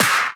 clap02.ogg